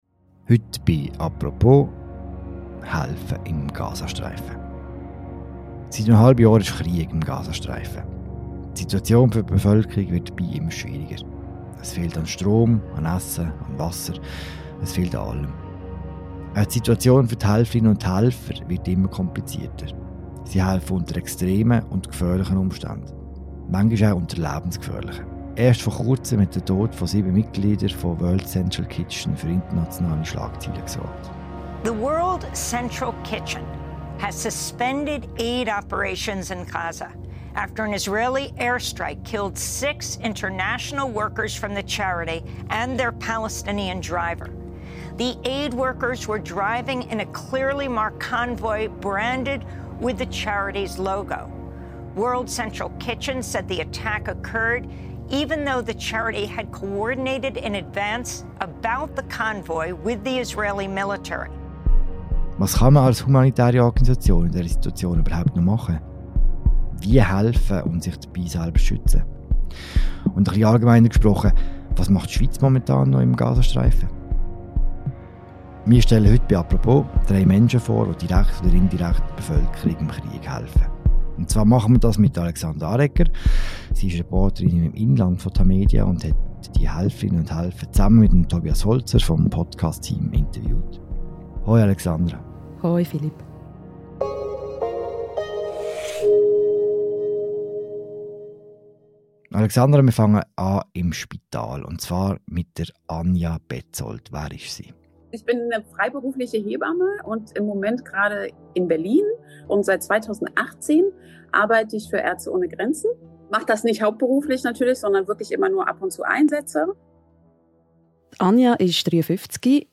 «Apropos» stellt drei Menschen vor, die vor Ort humanitäre Hilfe leisten oder diese aus der Schweiz heraus koordinieren.